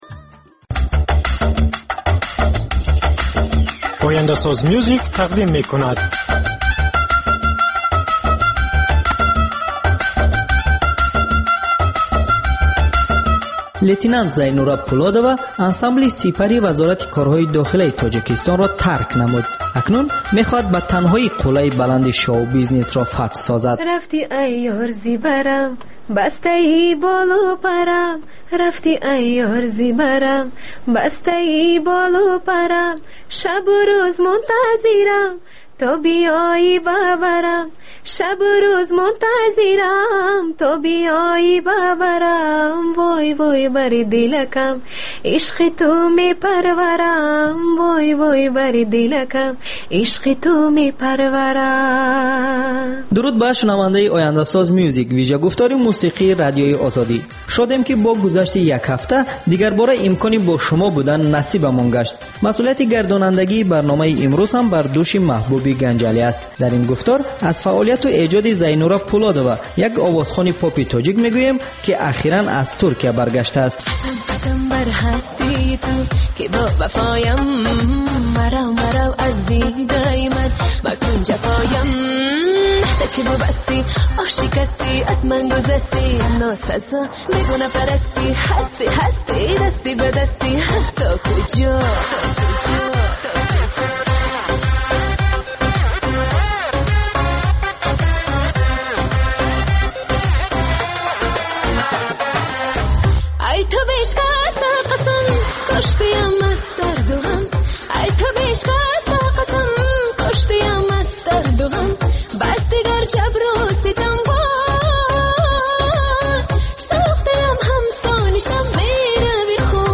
Ахбори фарҳангӣ, гуфтугӯ бо овозхонони саршинос, баррасии консерт ва маҳфилҳои ҳунарӣ, солгарди ходимони ҳунар ва баррасии саҳми онҳо.